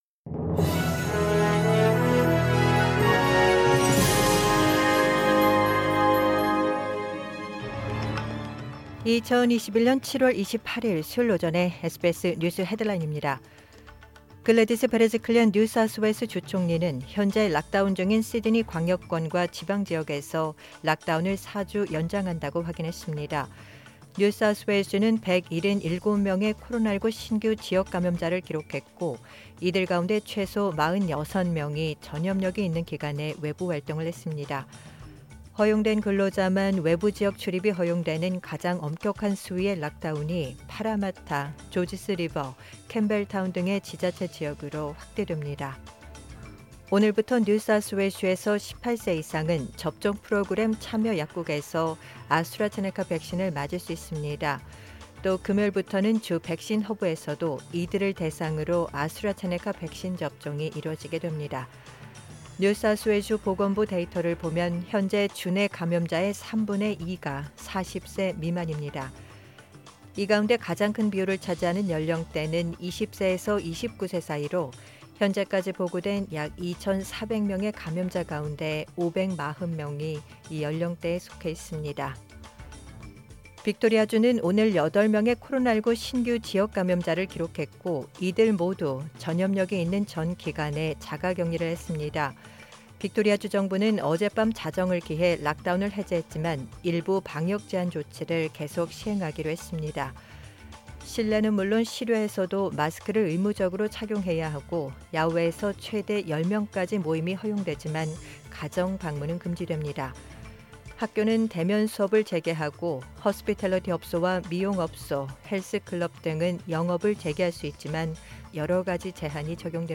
2021년 7월 28일 수요일 오전의 SBS 뉴스 헤드라인입니다.